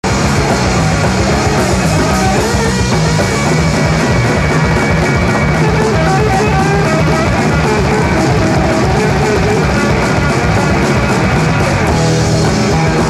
エネルギッシュ。